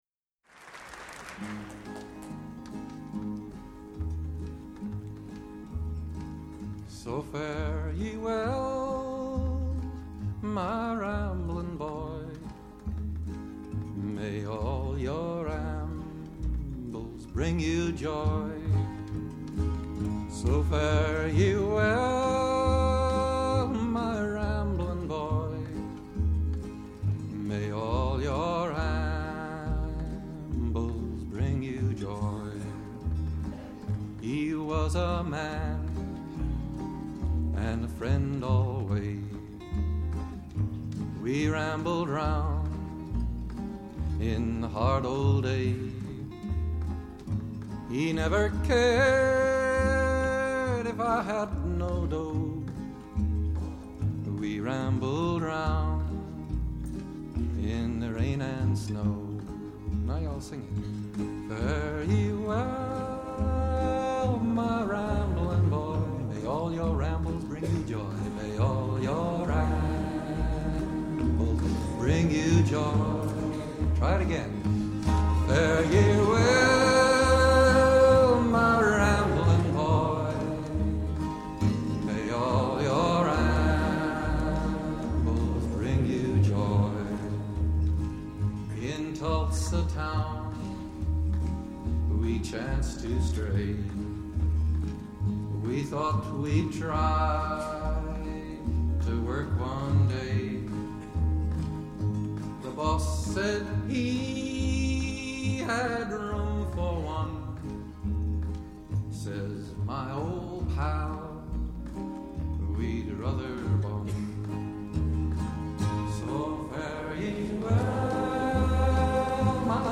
★ 發燒友瘋狂推薦，栩栩如生、歷歷在目的錄音！
★ 類比之聲的示範級錄音，最溫暖豐富的活生感！